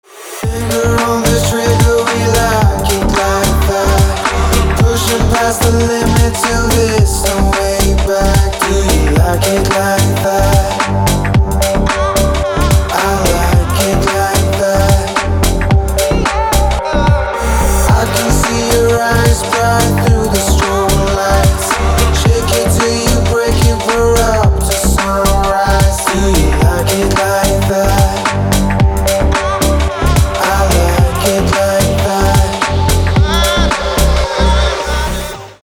• Качество: 320, Stereo
мужской вокал
deep house
dance
электронная музыка
спокойные